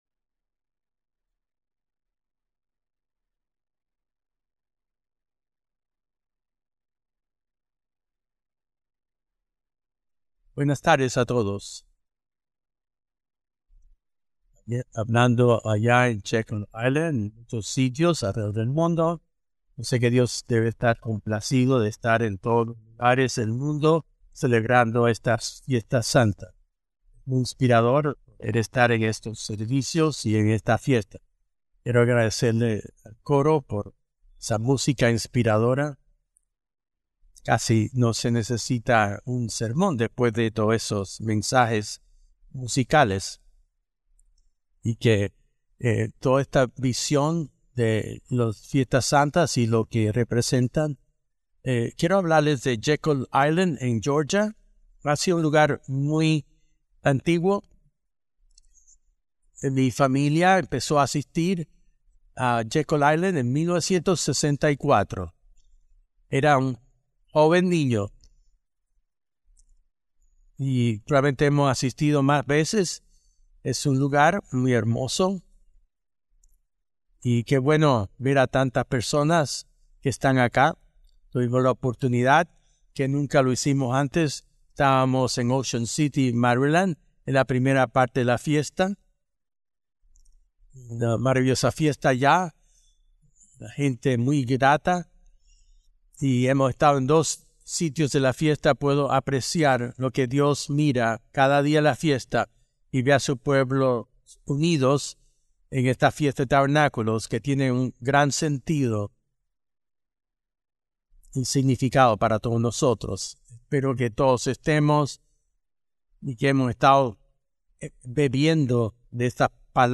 Sermones